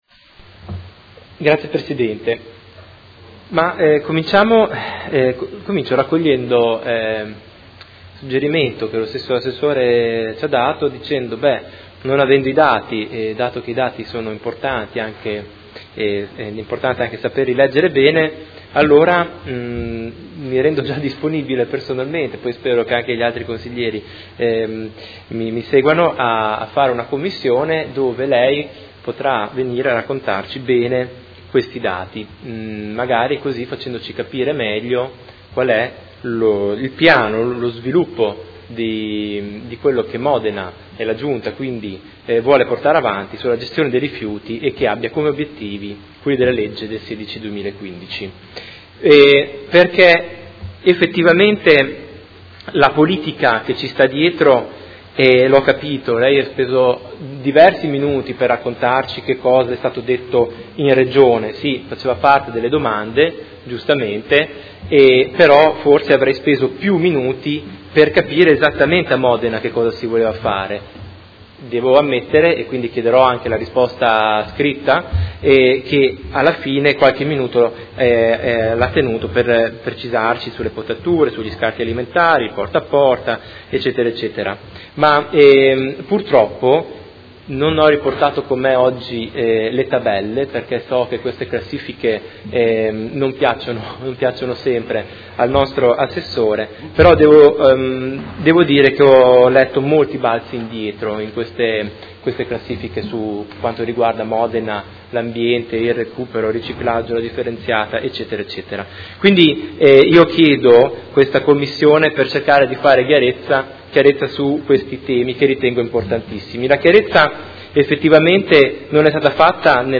Seduta del 1/12/2016 Interrogazione del Gruppo Per Me Modena avente per oggetto: Ulteriori 30.000 tonnellate di rifiuti da smaltire all’inceneritore di Modena e sostenibilità della gestione dei rifiuti. Dibattito